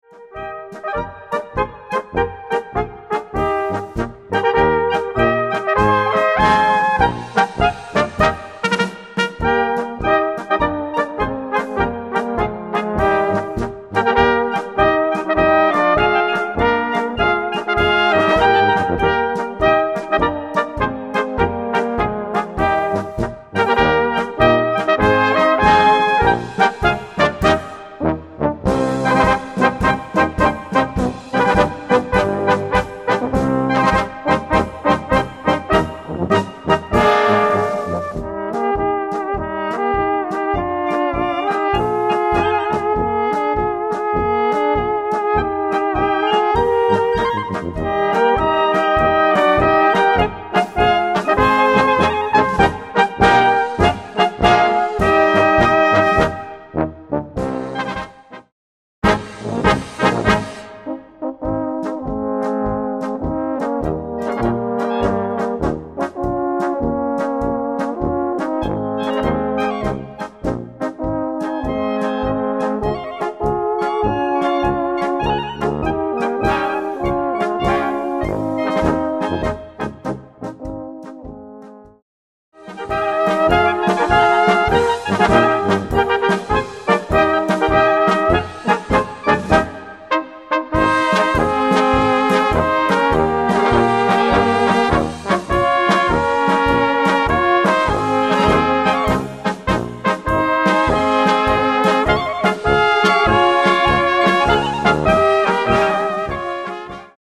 Gattung: Polka
Besetzung: Blasorchester
Eine traumhaft gemütliche Polka